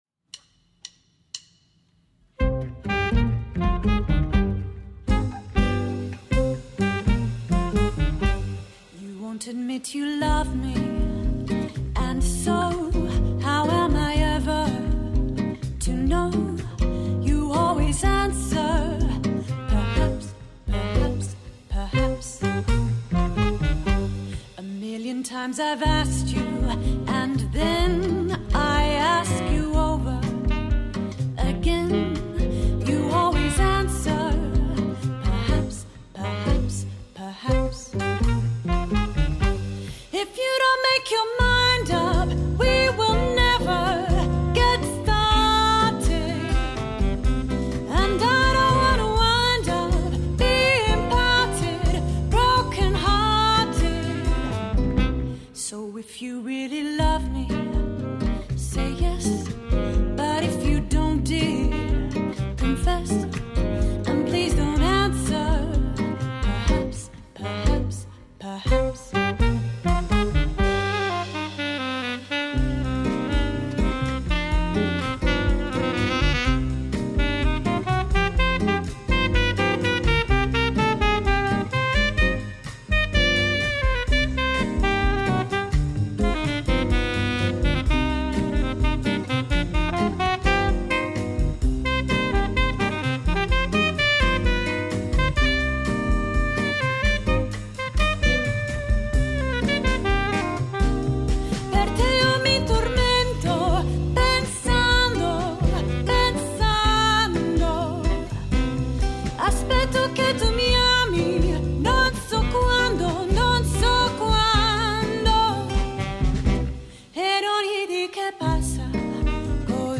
vintage swing and Latin jazz band based in London.